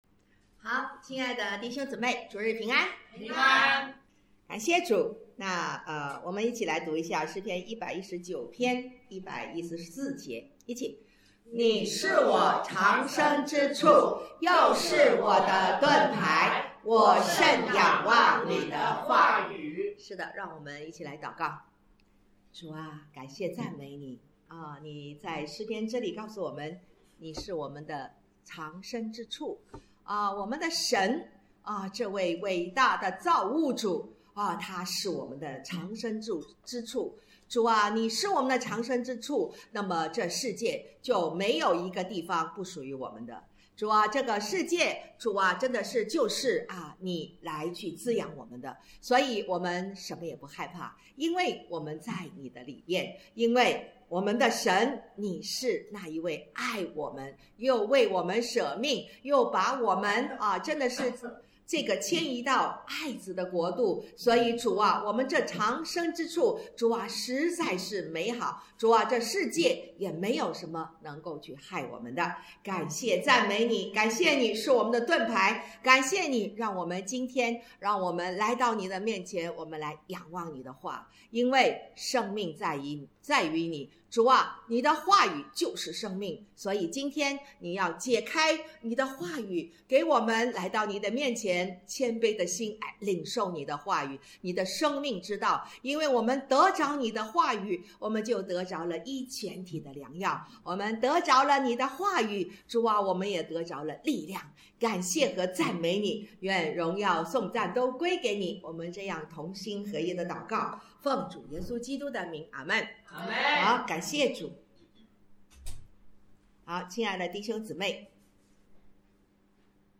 讲道录音 点击音频媒体前面的小三角“►”就可以播放了 https